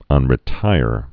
(ŭnrĭ-tīr)